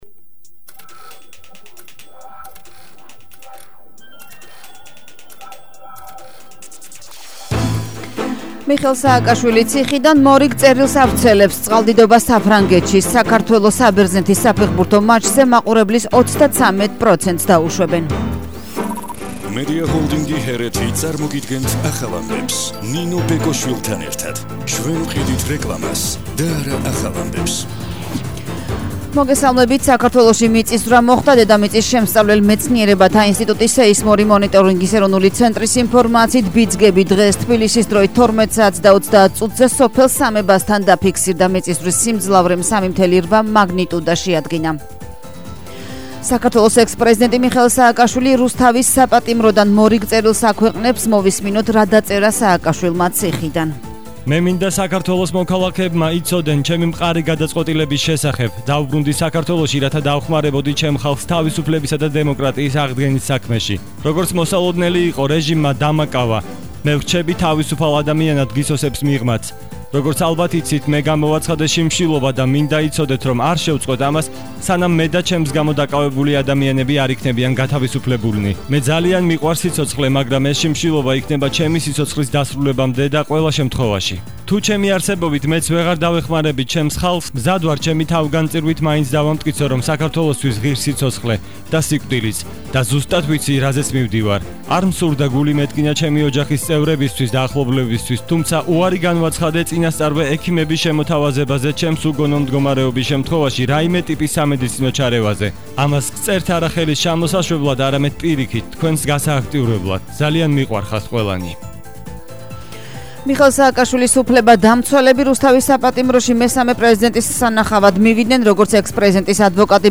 ახალი ამბები 13:00 საათზე –05/10/21